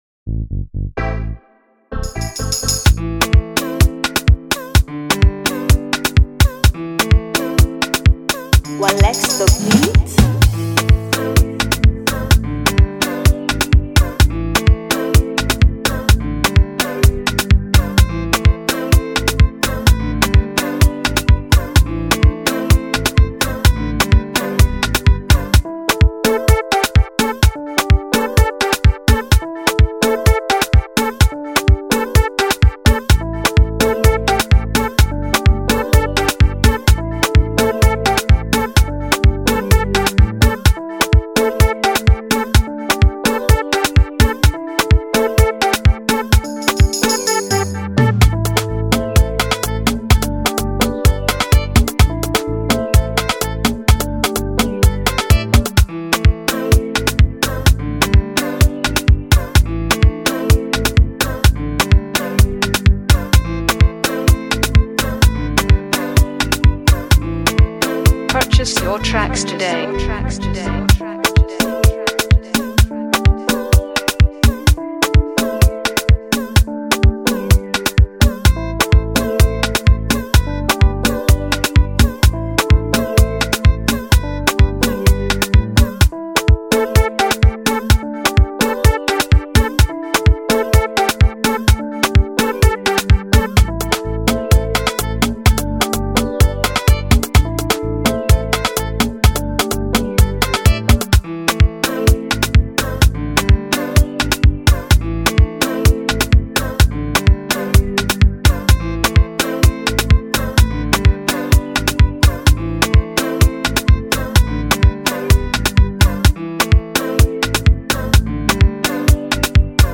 2021-08-16 1 Instrumentals 0
Dance hall free beat instrumental